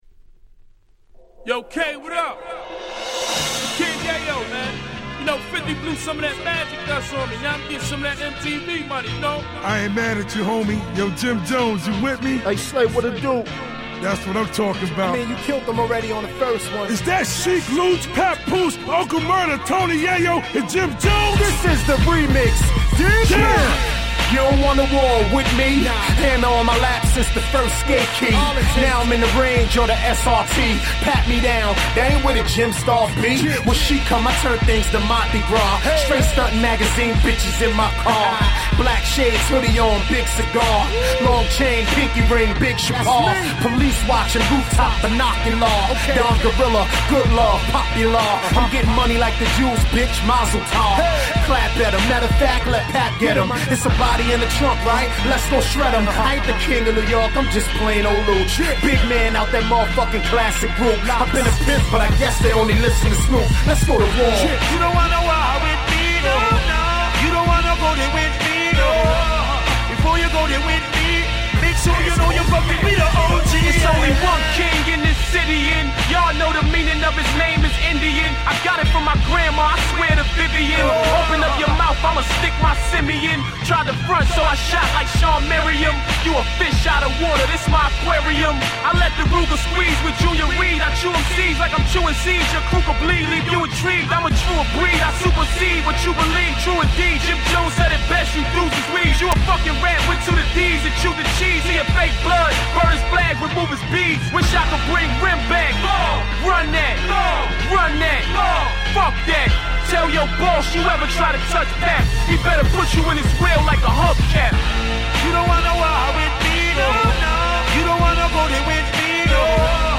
10' Nice Hip Hop !!